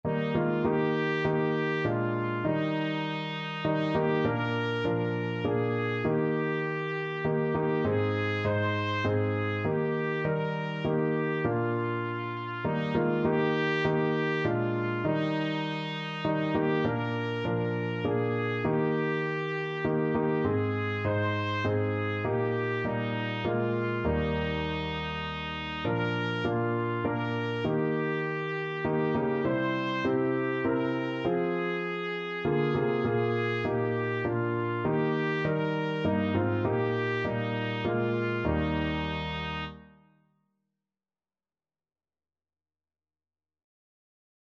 Christian
3/4 (View more 3/4 Music)